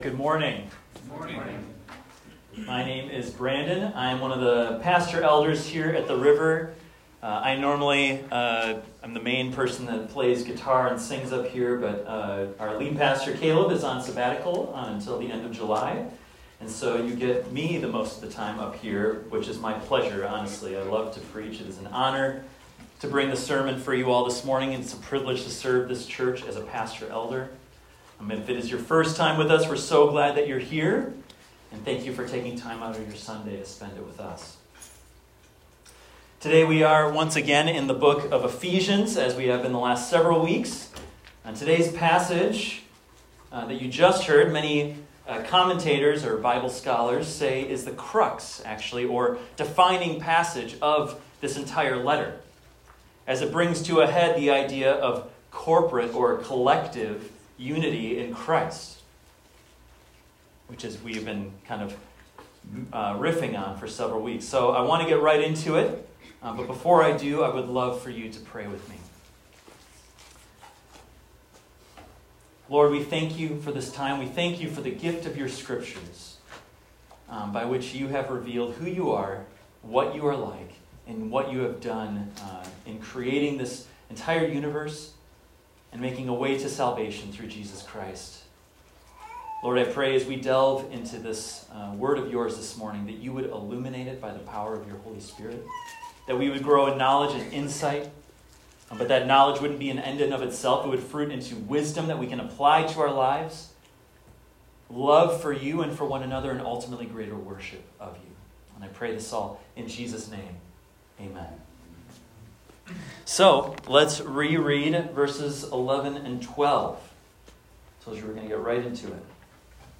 This is a recording of a sermon titled, "One in Christ."